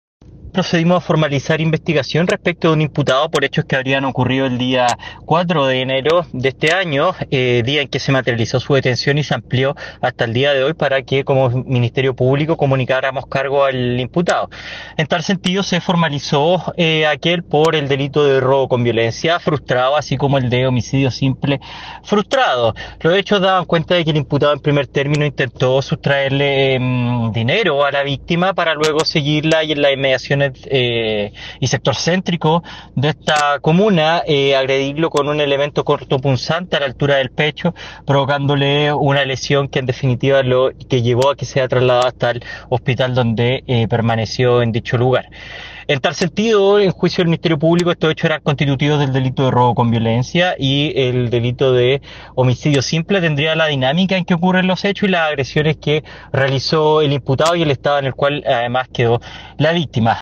Sin embargo, al quedar en libertad al día siguiente cometió este asalto a un transeúnte a quien además atacó con un arma blanca, dejándolo malherido, siendo más tarde aprehendido por Carabineros, como indicó el fiscal Andreas Kush.